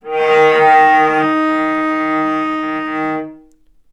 vc_sp-D#3-ff.AIF